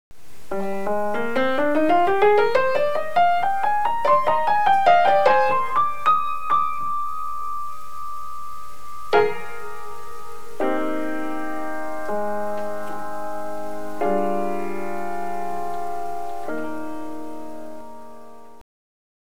the pianist starts and ends the piece***